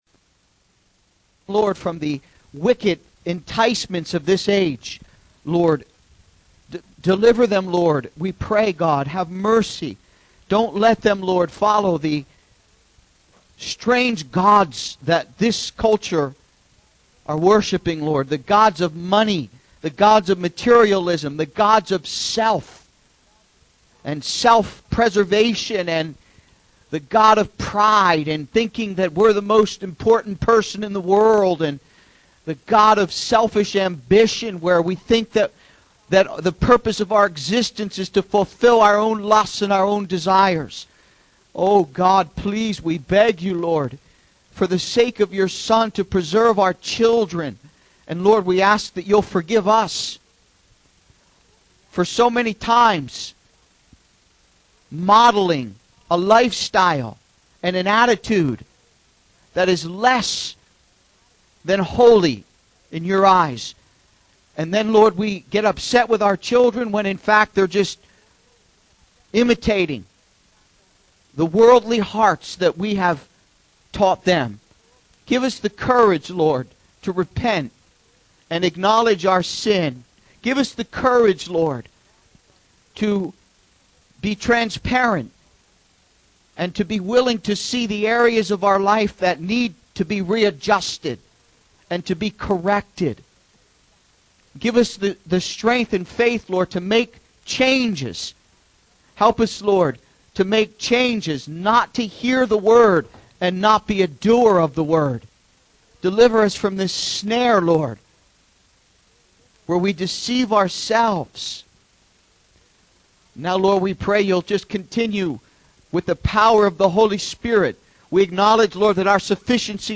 He calls for repentance from the worldly idols of pride, materialism, and self-preservation that distract us from our spiritual calling. The preacher urges believers to approach God with humility, acknowledging their need for His grace and the necessity of serving others selflessly without expecting rewards. He highlights the need for accountability, transparency, and vulnerability within the body of Christ to foster genuine fellowship and spiritual growth.